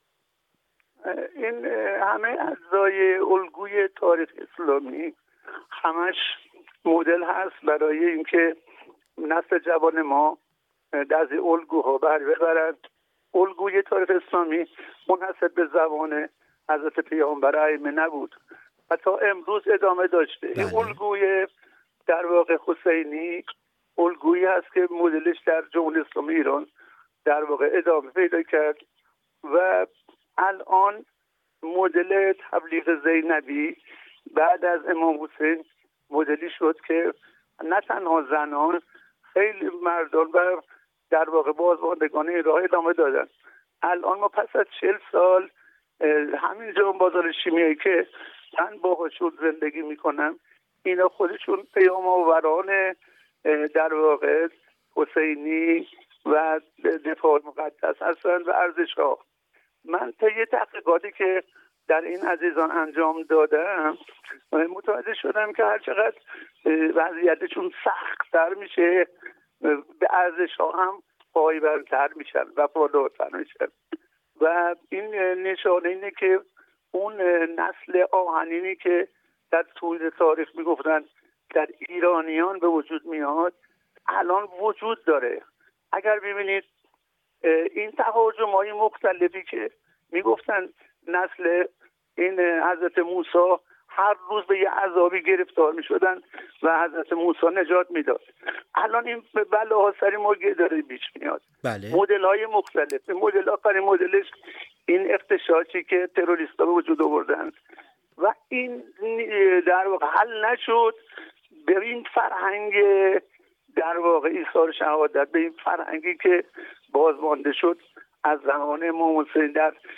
حاصل این گفت‌وگوی تلخ اما صریح و صادق در ادامه از خاطر مخاطبان می‌گذرد.